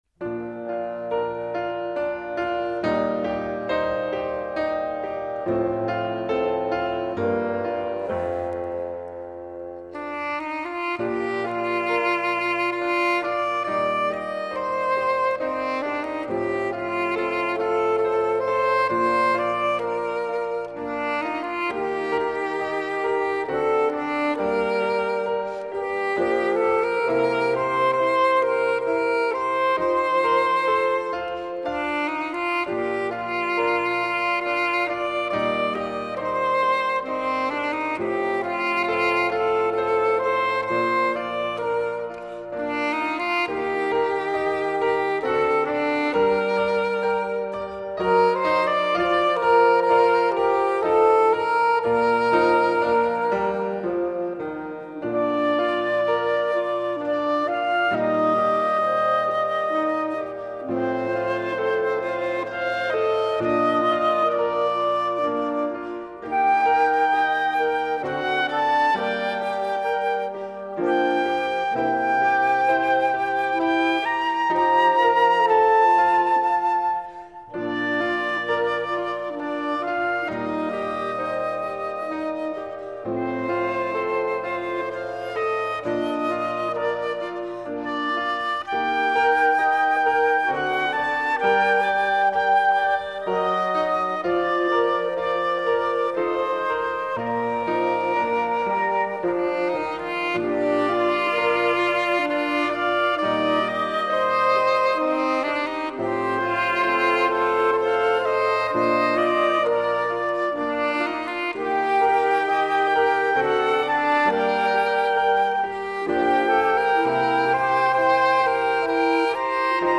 La chanson de la primaire
Cantiques pour les enfants.